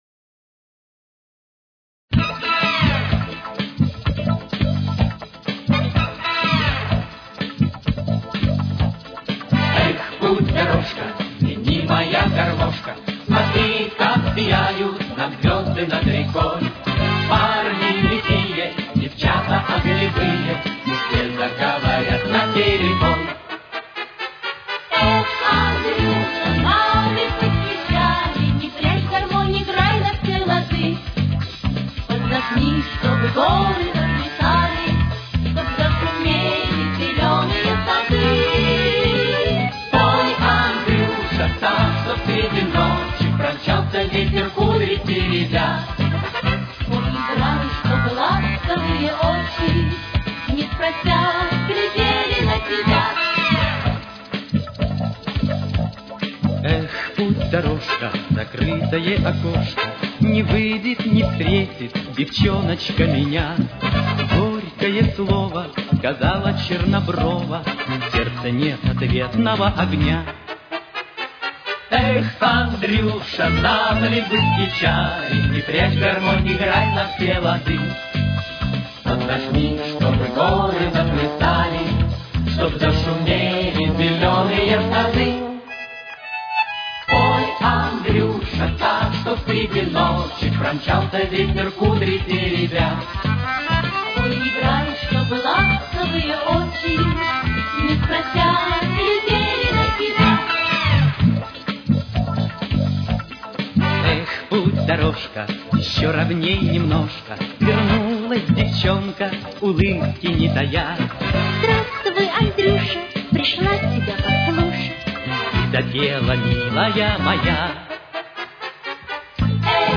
с очень низким качеством (16 – 32 кБит/с)
Тональность: Ля минор. Темп: 131.